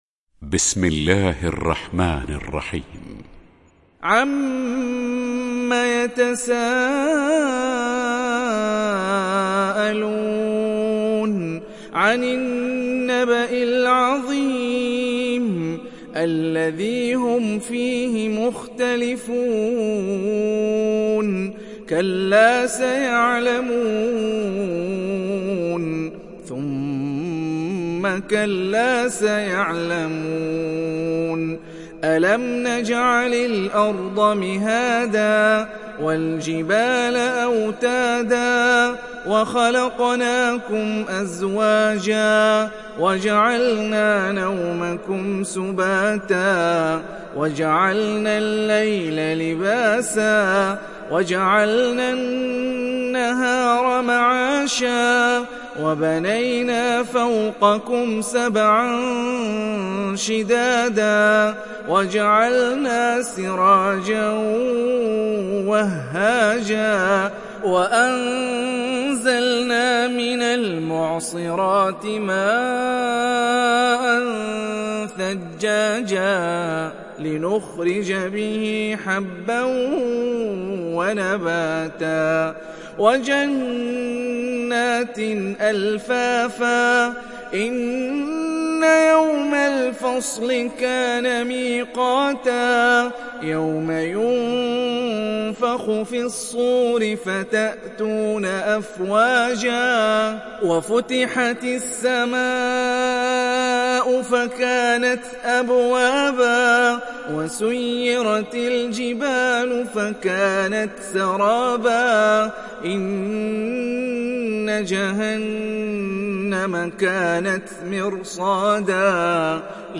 Nebe Suresi İndir mp3 Hani Rifai Riwayat Hafs an Asim, Kurani indirin ve mp3 tam doğrudan bağlantılar dinle